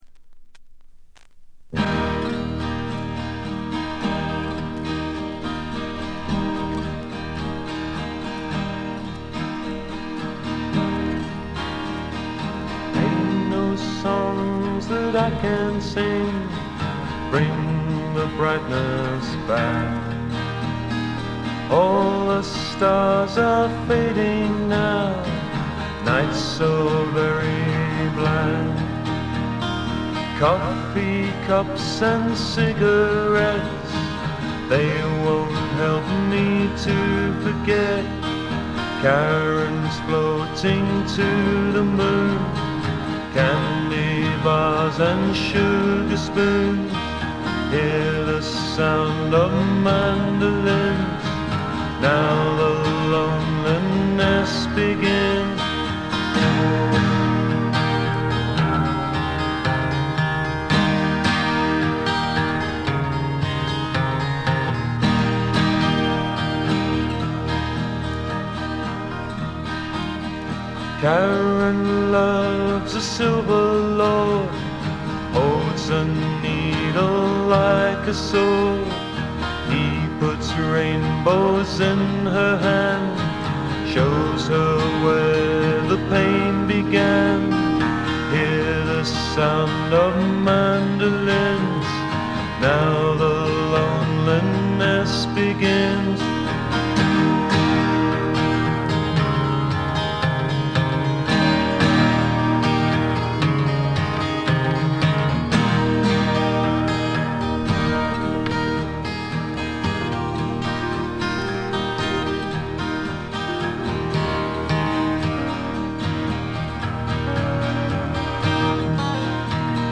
部分視聴ですが、ほとんどノイズ感無し。
全編を通じて飾り気のないシンプルな演奏で「木漏れ日フォーク」ならぬ「黄昏フォーク」といったおもむきですかね。
試聴曲は現品からの取り込み音源です。
Recorded At - Mid Wales Sound Studios